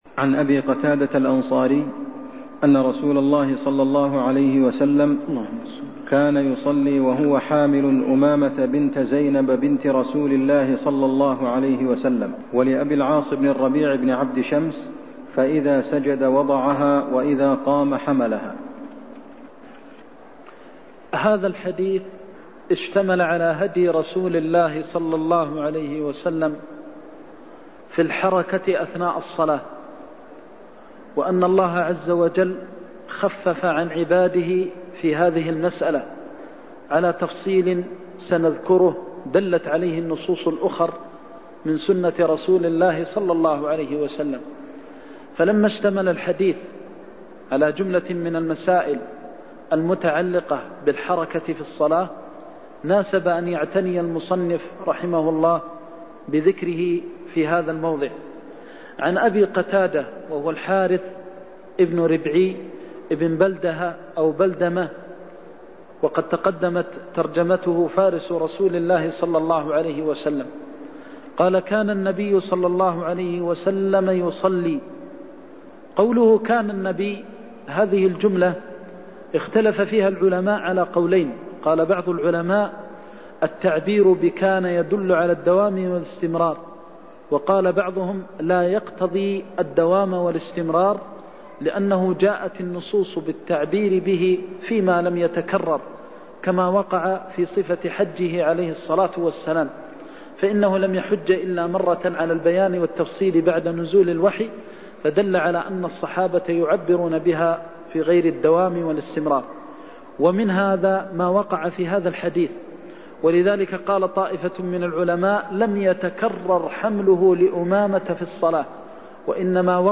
المكان: المسجد النبوي الشيخ: فضيلة الشيخ د. محمد بن محمد المختار فضيلة الشيخ د. محمد بن محمد المختار كان يصلى وهو حامل أمامة (90)